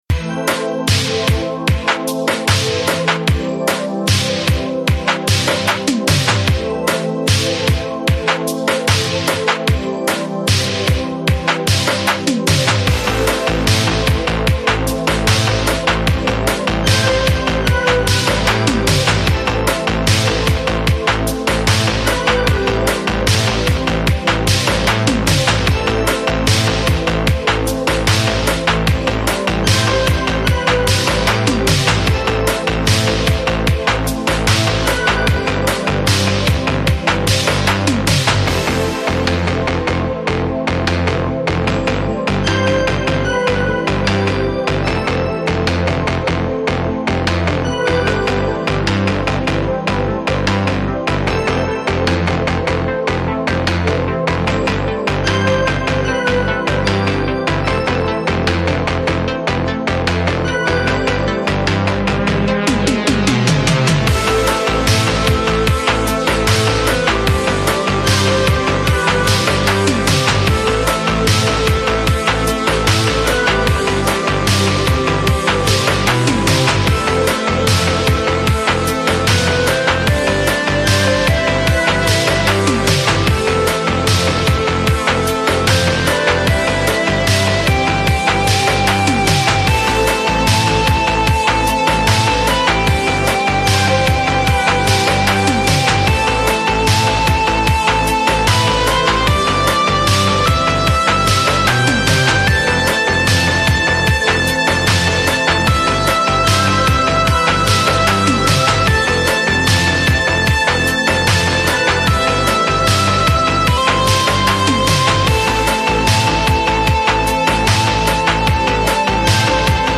BPM75
Audio QualityPerfect (High Quality)
Comments[MIAMI STYLE AMBIENT]